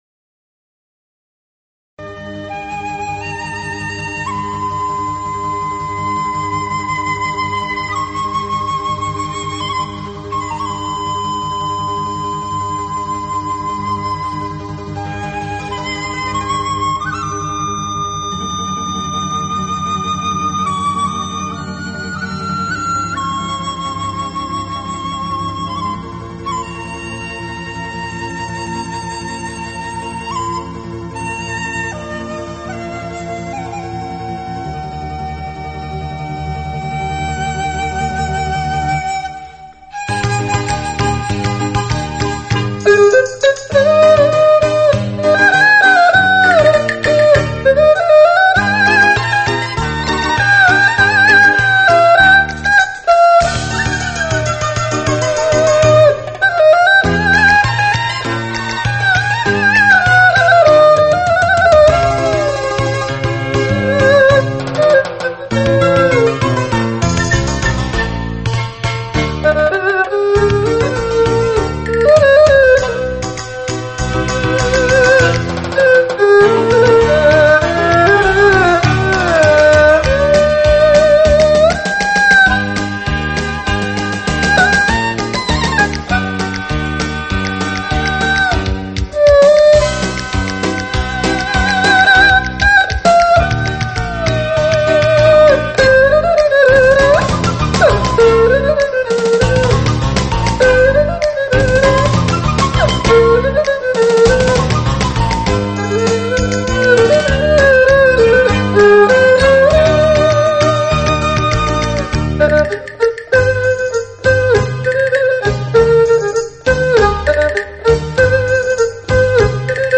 现代佳丽组合的民族音乐跨界远征
古典乐器演绎新民乐曲风绚丽多采
古雅为本时尚为体 彰显现代东方极致风韵